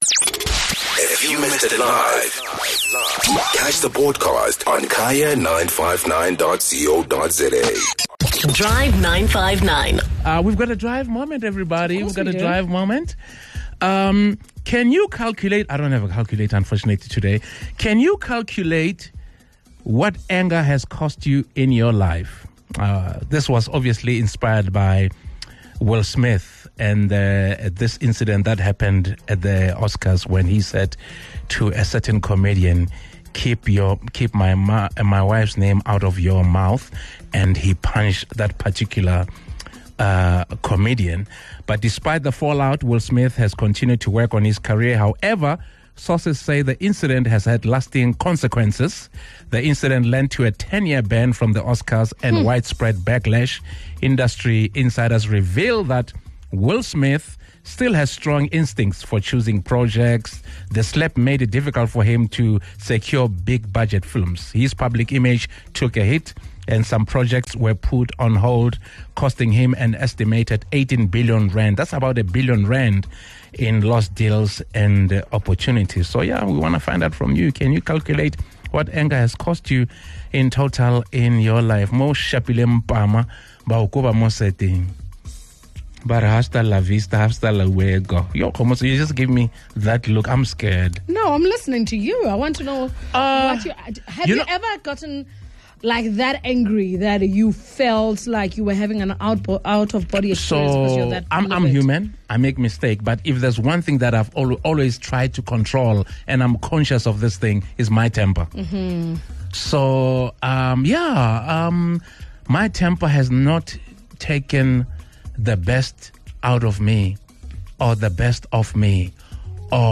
From losing money to losing people, what has anger cost you? Take a listen to what the Drive team and our listeners had to say.